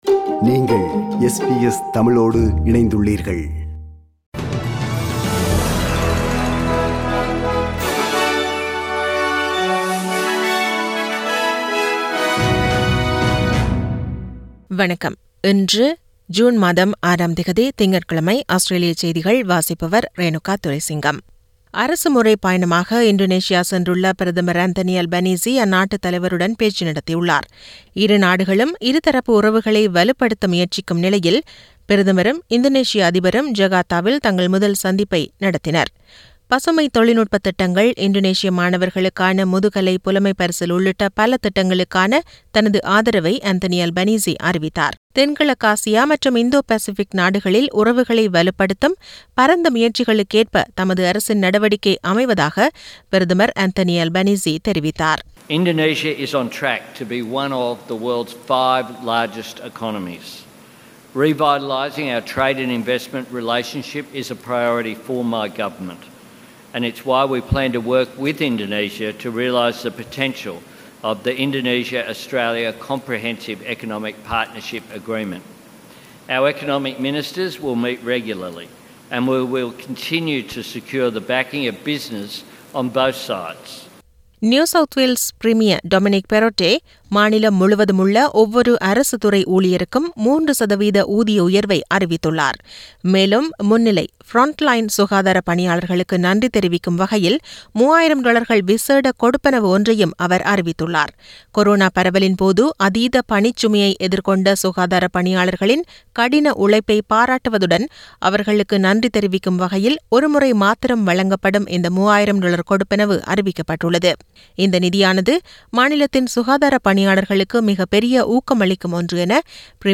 Australian news bulletin for Monday 06 June 2022.